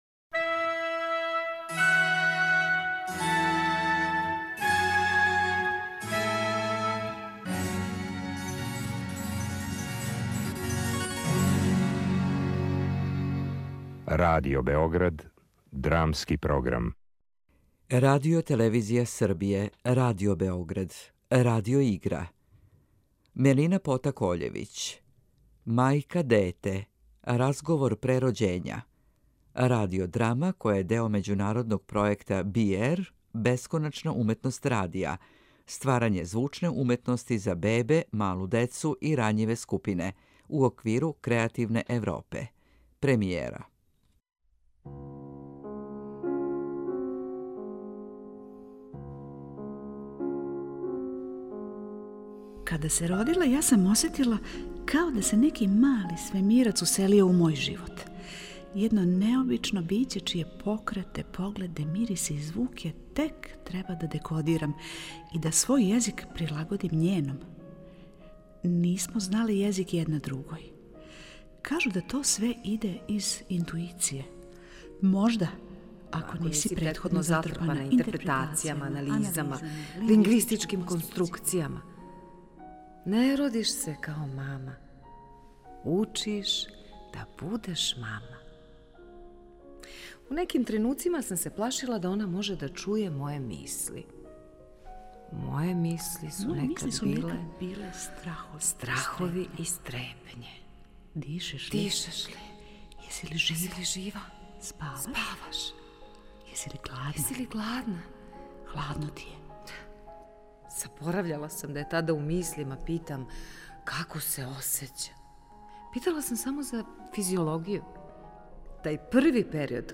Ovaj radiofonski kolaž jedna je od emisija s kojom Radio Beograd i Redakcija dramskog programa Radio Beograda učestvuju u projektu B-AIR: Art Infinity Radio - Creating sound art for babies, toddlers and vulnerable groups, u okviru Creative Europe od oktobra 2020. godine.
Radio - igra
Majke različitih profesionalnih orijentacija i godišta govore o sopstvenim iskustvima na razne zadate teme roditeljstva.